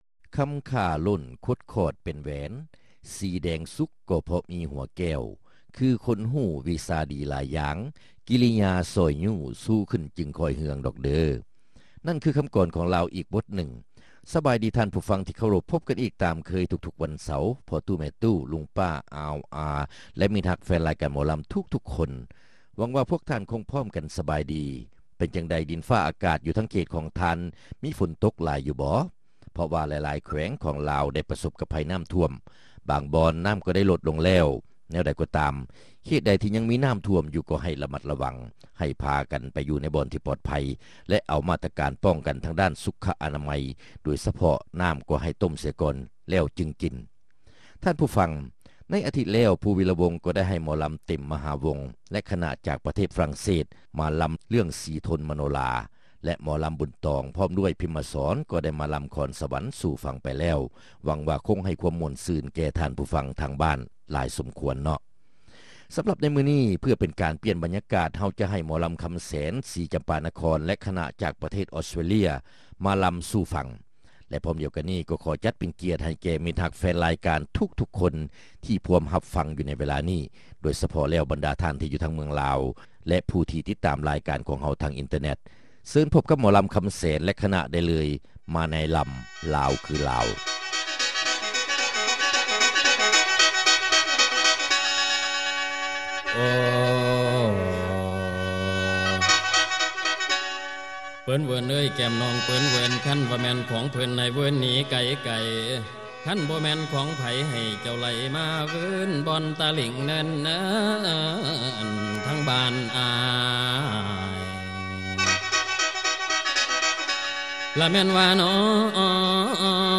ຣາຍການໜໍລຳ ປະຈຳສັປະດາ ວັນທີ 26 ເດືອນ ສິງຫາ ປີ 2005